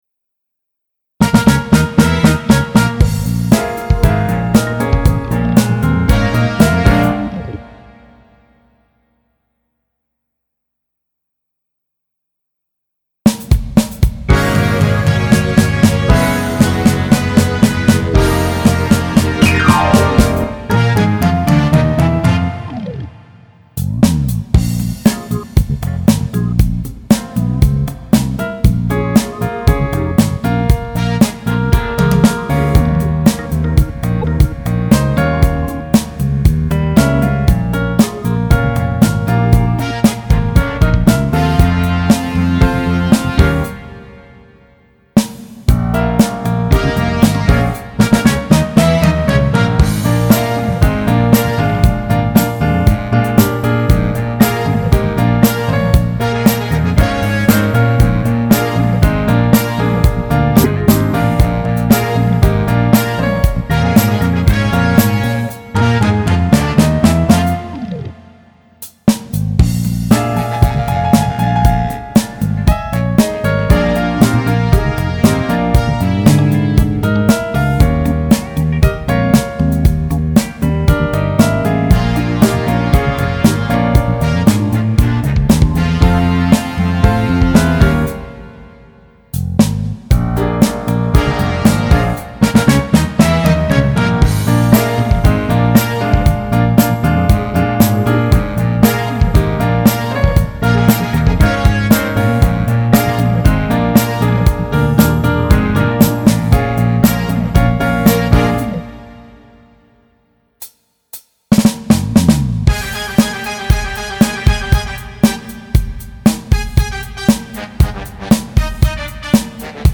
음정 -2키
장르 가요 구분 Pro MR